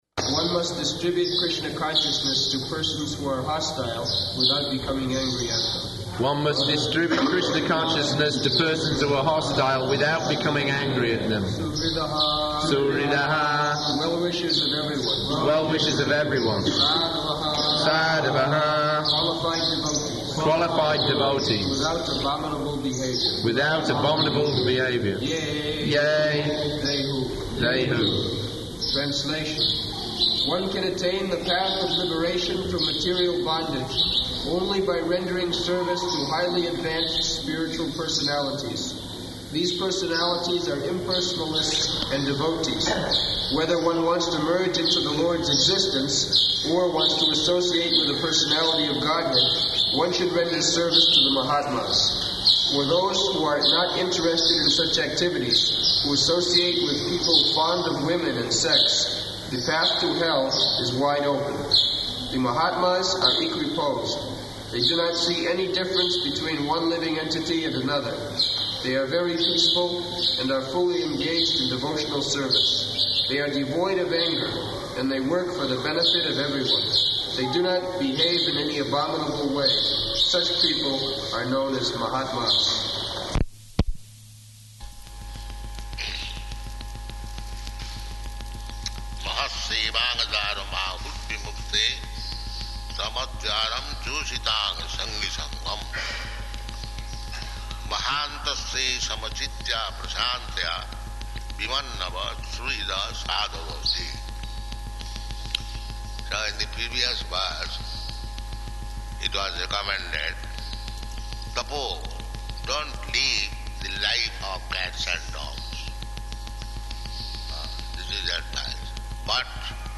Location: Vṛndāvana
[leads chanting of synonyms]